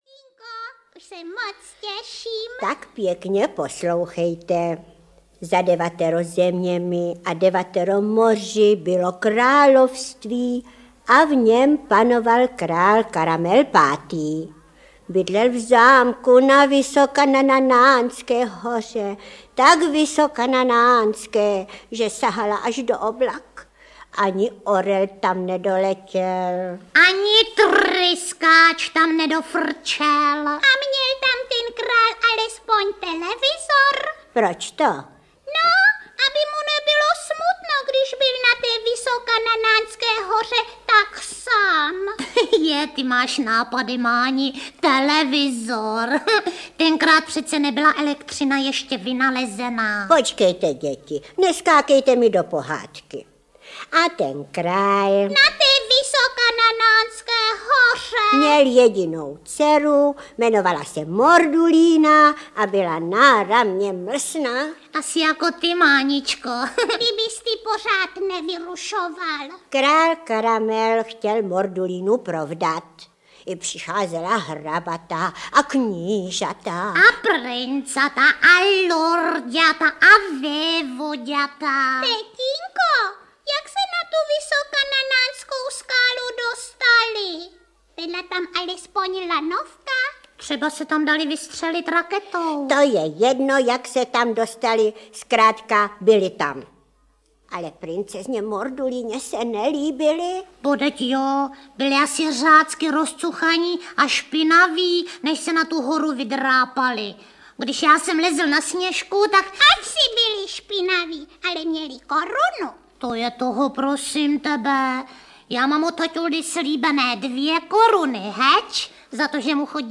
Album pohádek "Supraphon dětem" 18 audiokniha
Ukázka z knihy